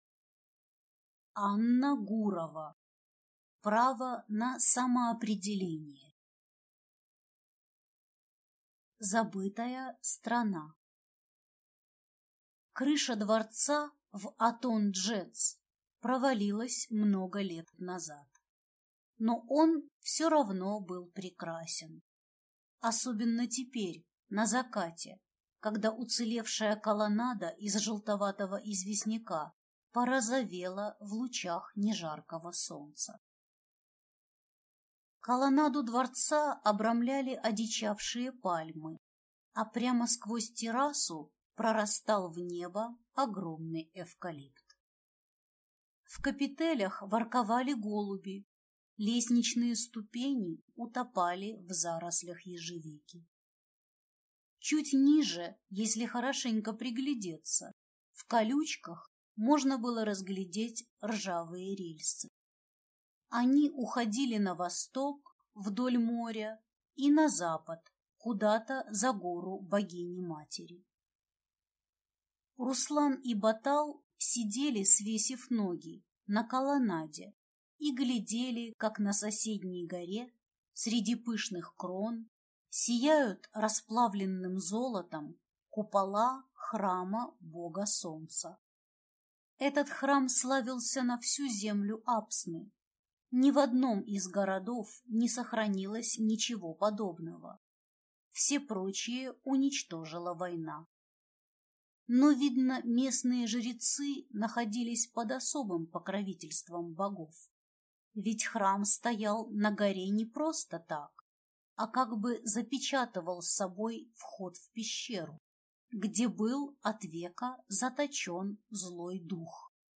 Аудиокнига Право на самоопределение | Библиотека аудиокниг
Прослушать и бесплатно скачать фрагмент аудиокниги